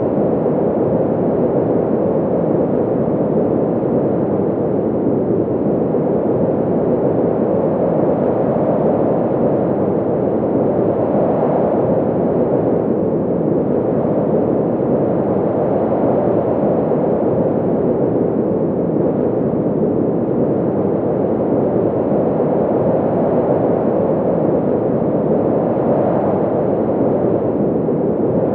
Ambiance_Wind_2.wav